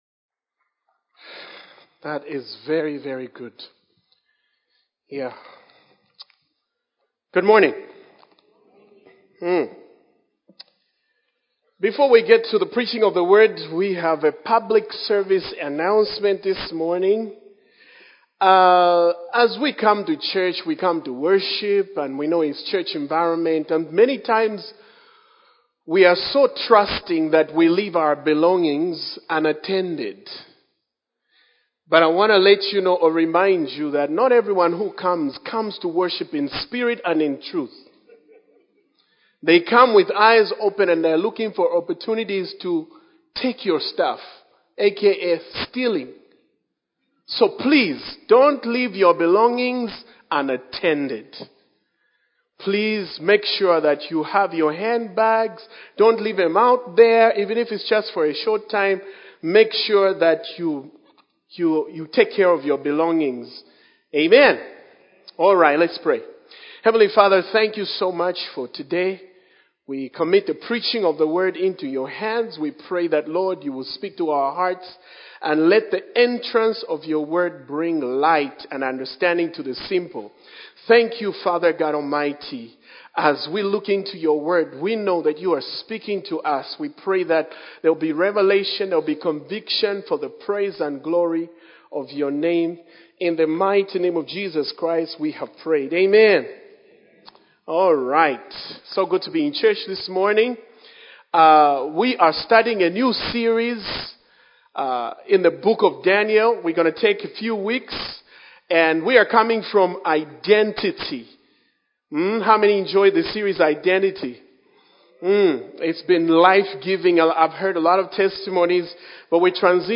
A message from the series "The Book of Daniel."